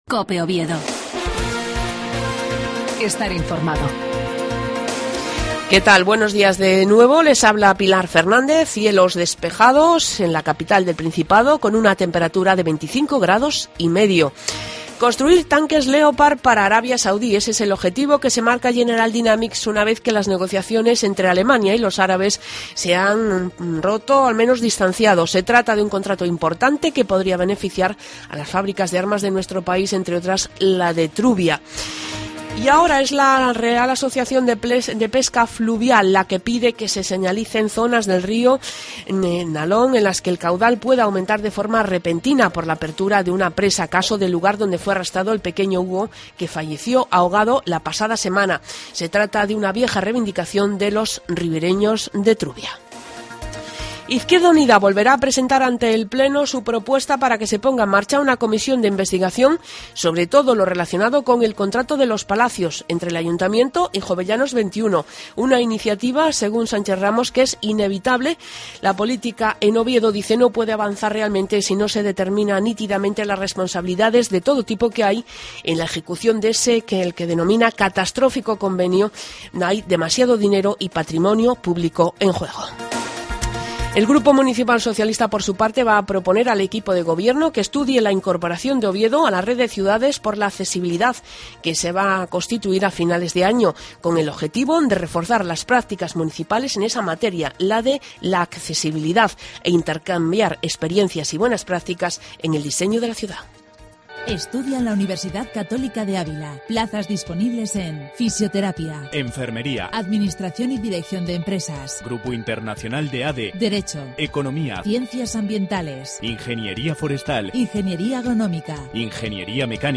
AUDIO: LAS NOTICIAS DE OVIEDO A PRIMERA HORA DE LA MAÑANA.